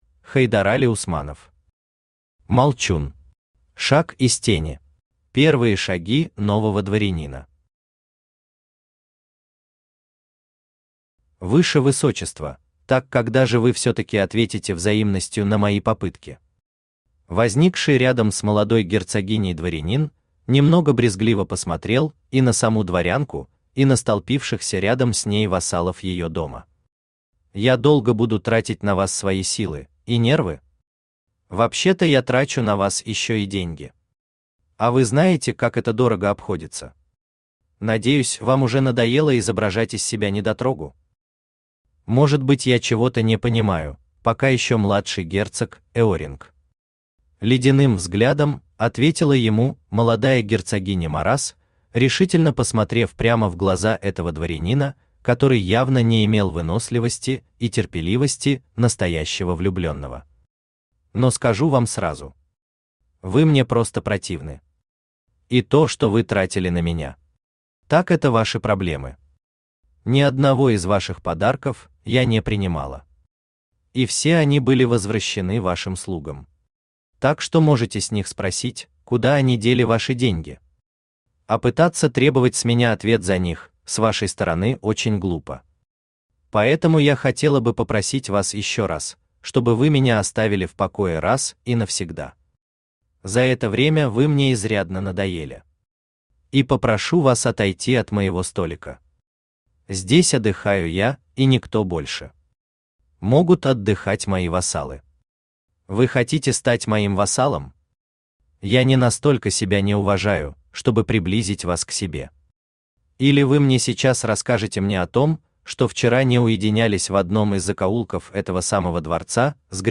Аудиокнига Молчун. Шаг из тени | Библиотека аудиокниг
Шаг из тени Автор Хайдарали Усманов Читает аудиокнигу Авточтец ЛитРес.